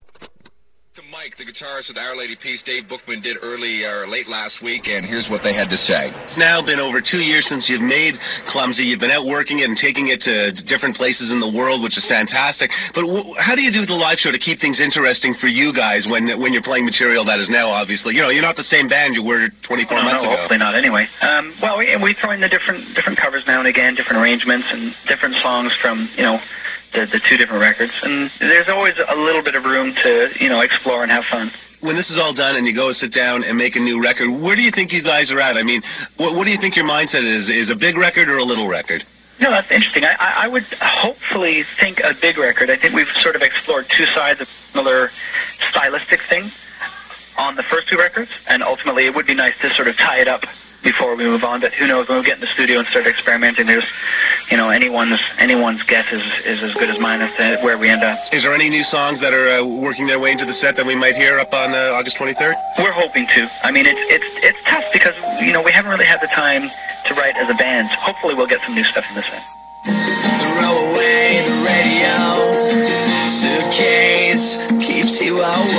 Live Interview